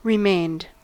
Ääntäminen
Ääntäminen US : IPA : [ɹɪˈmeɪnd] Haettu sana löytyi näillä lähdekielillä: englanti Käännöksiä ei löytynyt valitulle kohdekielelle.